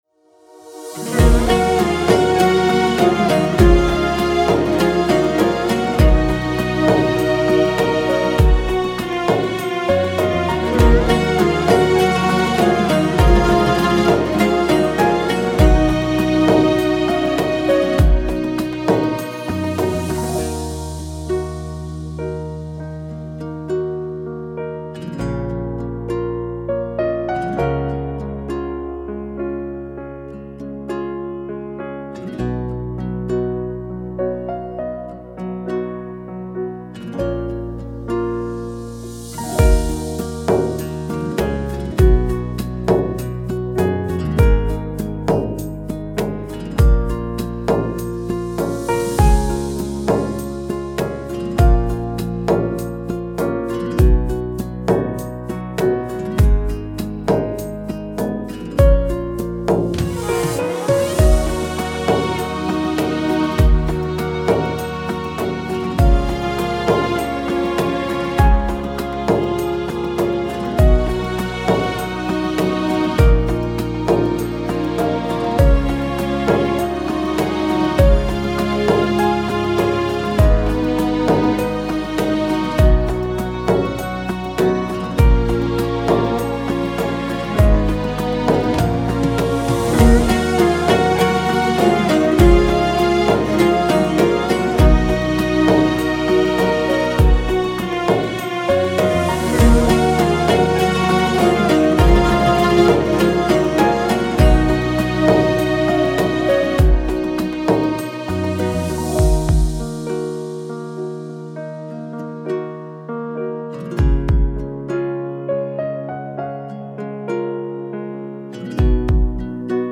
دانلود بیکلام